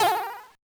jump2.ogg